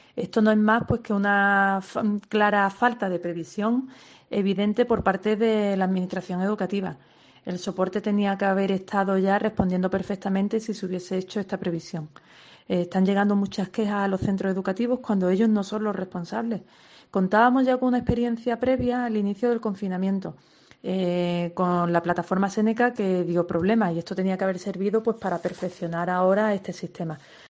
Estela Villalba, delegada de Educación